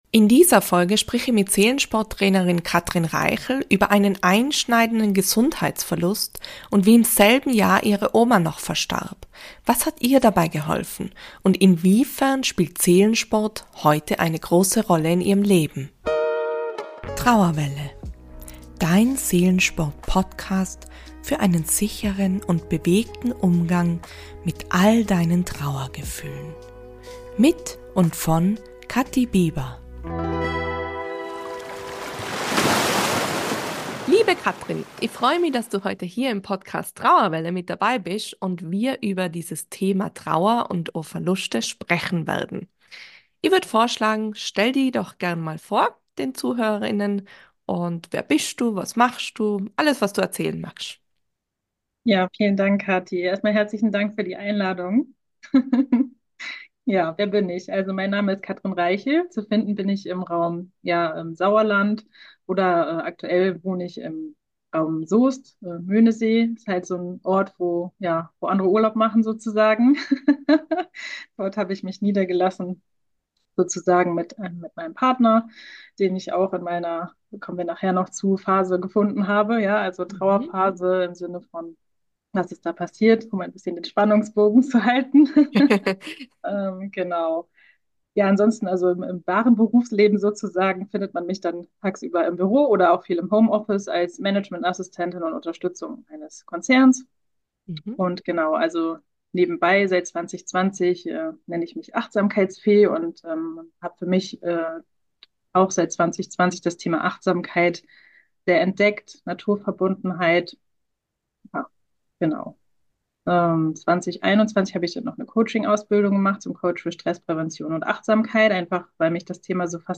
im Gespräch ~ Trauerwelle Podcast